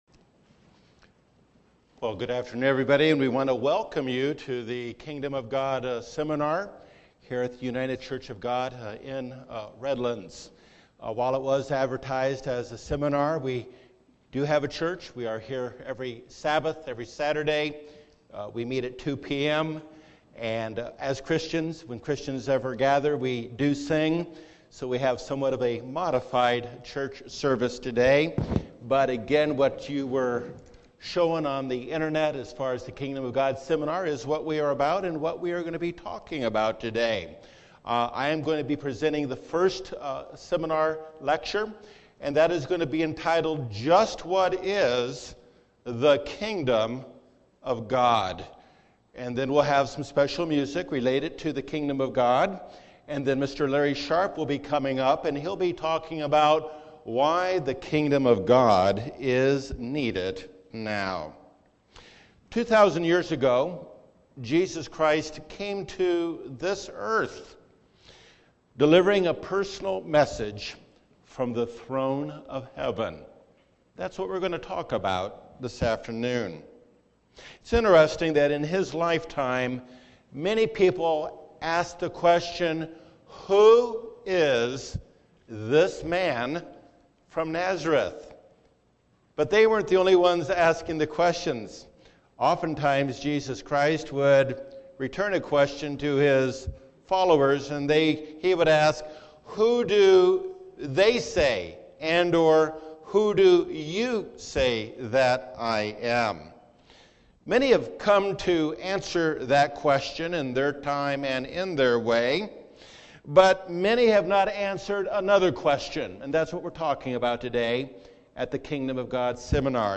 The message of Jesus was to create a change in the hearer. Learn more in this Kingdom of God seminar.